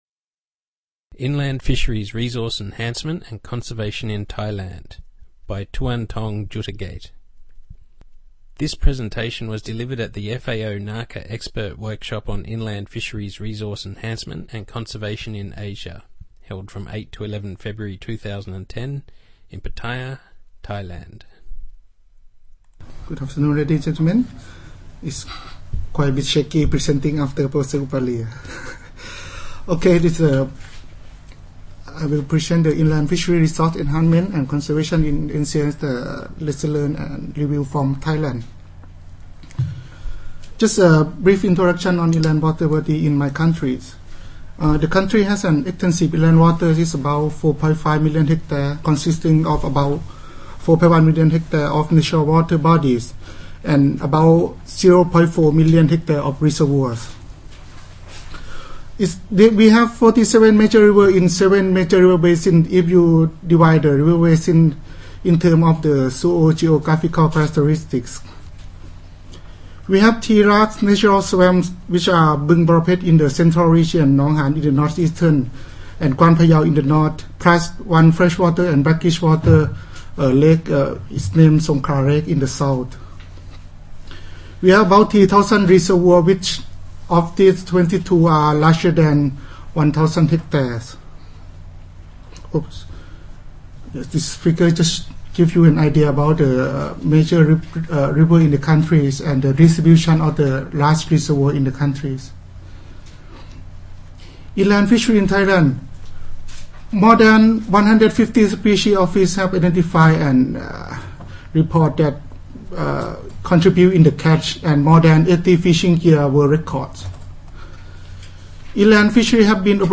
Presentation on inland fisheries resource enhancement and conservation in Thailand